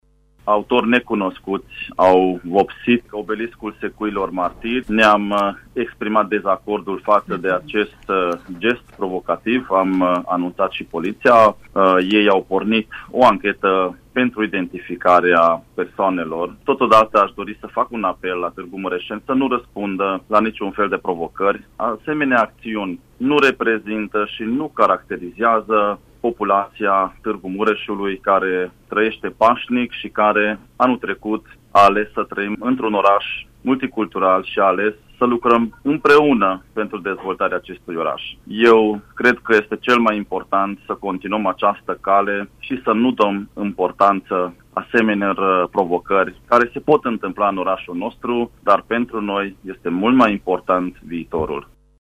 Primarul municipiului Târgu Mureș condamnă acest act și îndeamnă la bună înțelegere: